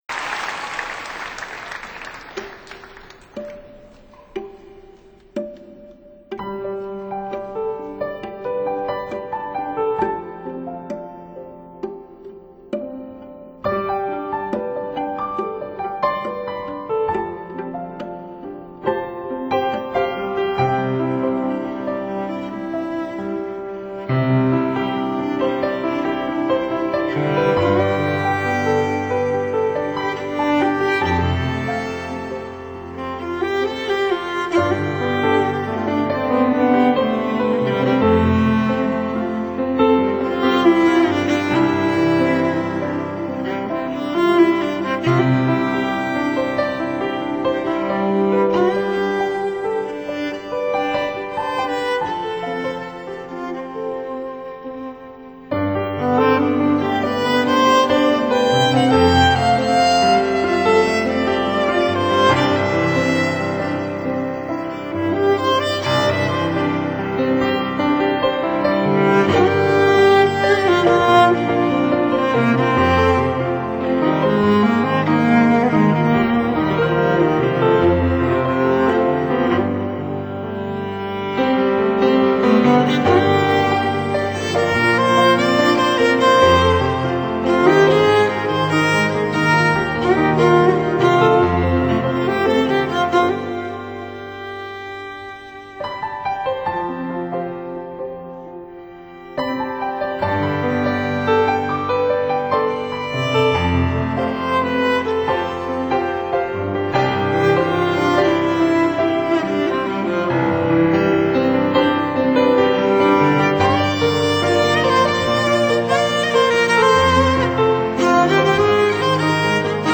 专辑主要是在2000年3月的盐湖城音乐会上现场录制的，再一次显示了他不一般的艺术天份。